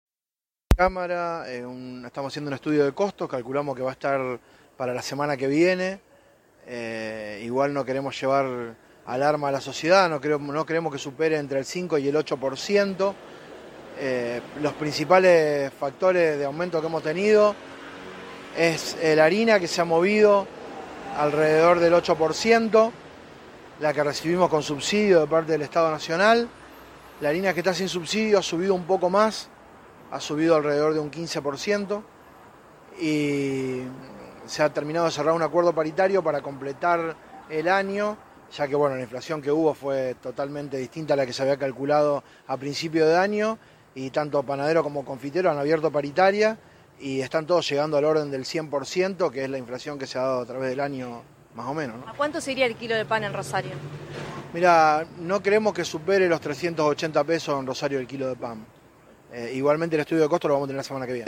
En diálogo con el móvil de LT8, reveló que en el año ya se registró un incremento del pan del 60% o 70%.